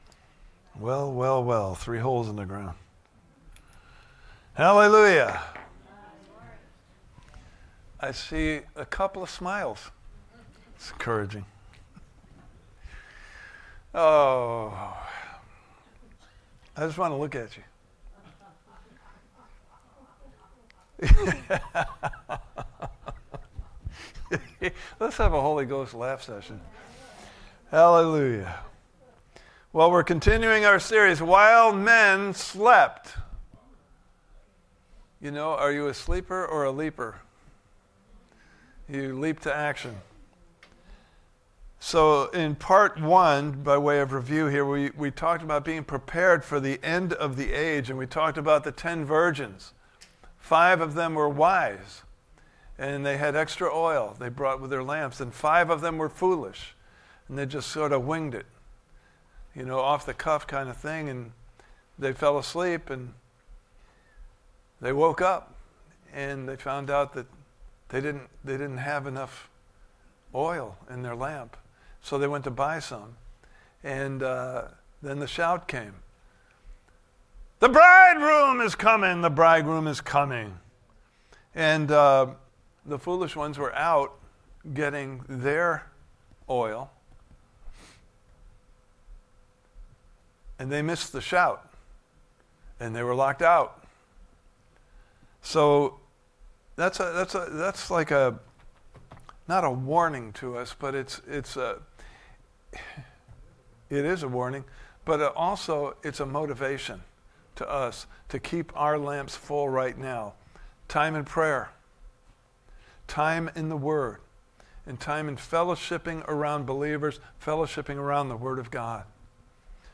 While Men Slept Service Type: Sunday Morning Service « Part 2